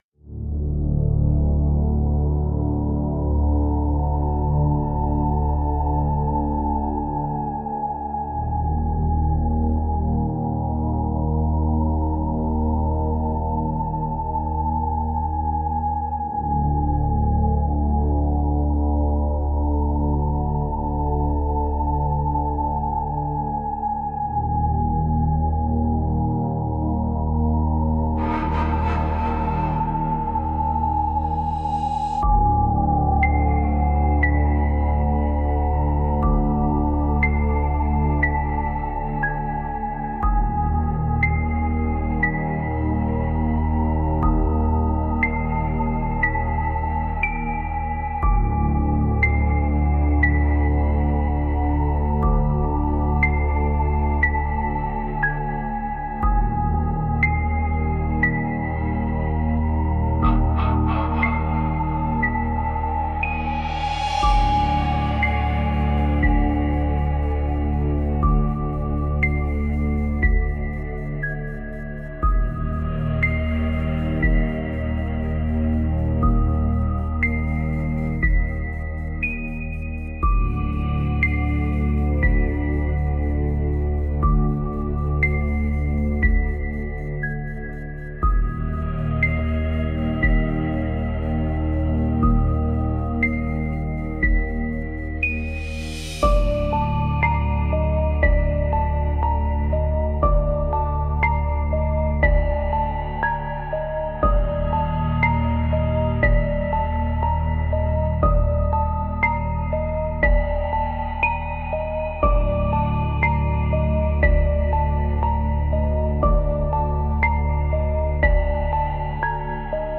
フリーBGM 🎶 静かな空間にじわじわと忍び寄る不安…耳元にまとわりつくような緊張感のあるホラー系BGMです。